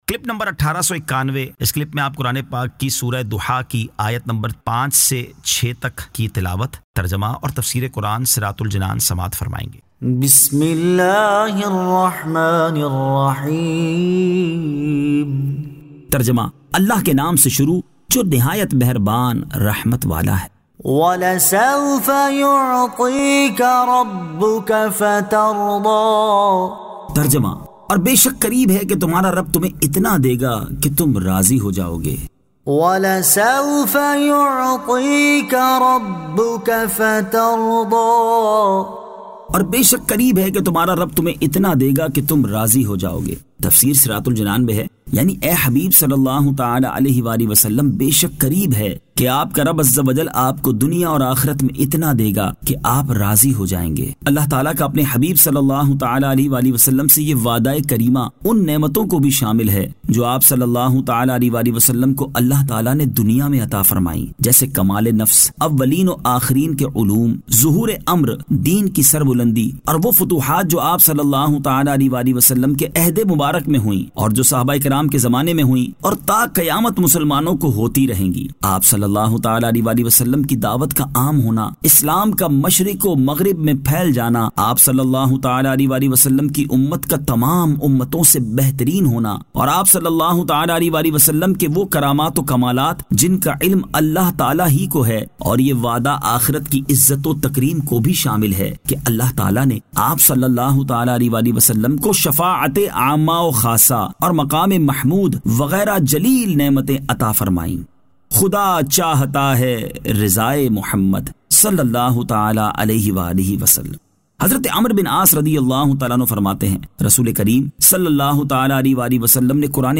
Surah Ad-Duhaa 05 To 06 Tilawat , Tarjama , Tafseer